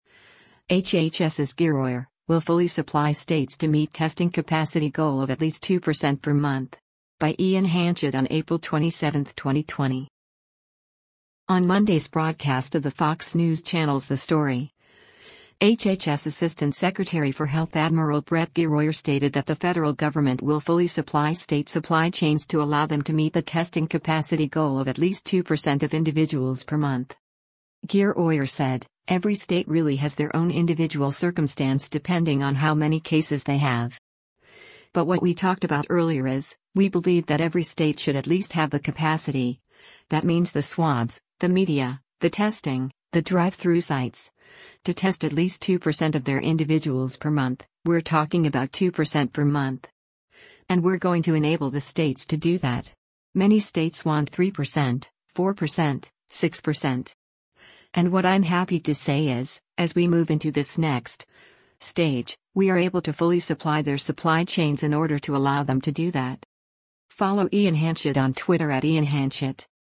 On Monday’s broadcast of the Fox News Channel’s “The Story,” HHS Assistant Secretary for Health Adm. Brett Giroir stated that the federal government will “fully supply” state supply chains to allow them to meet the testing capacity goal of at least 2% of individuals per month.